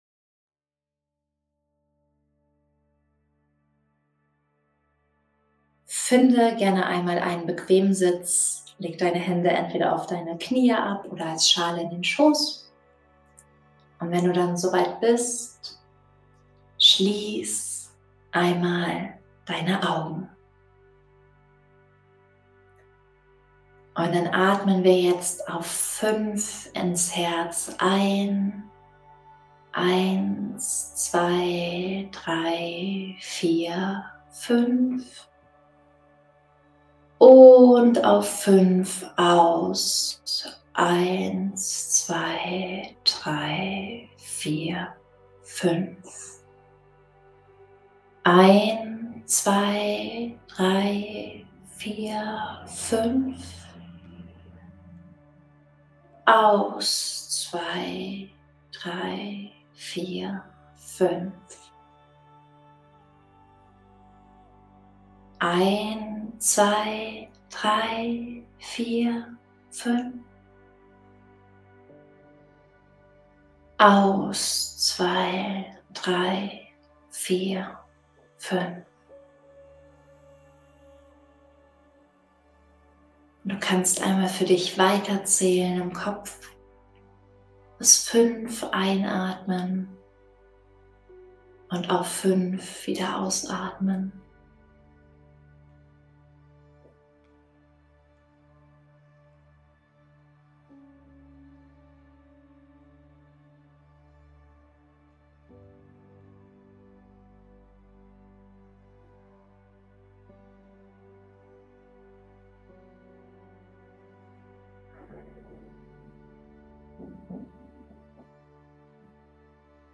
FYHJ_Day3_Meditation.mp3